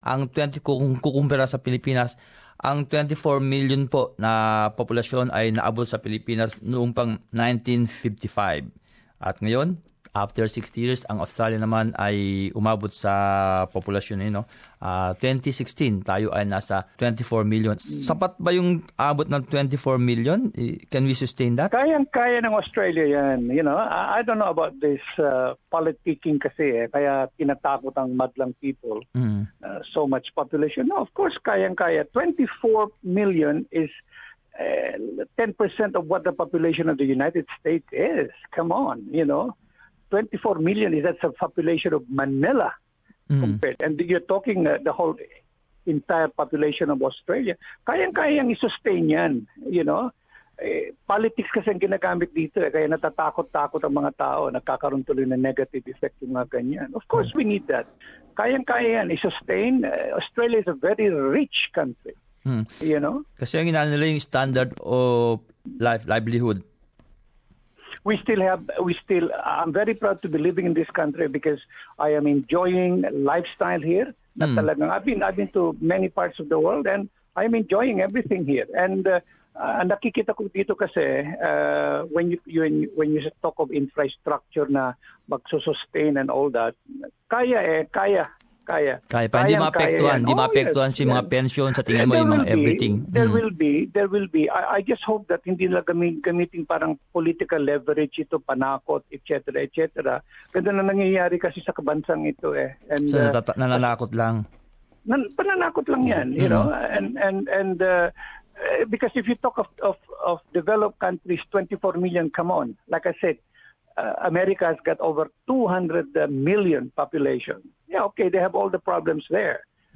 TALKBACK: Ito ang mga sinasabi ng atign mga taga-pakinig habang umabot na sa 24 Milyon ang populasyon ng bansa.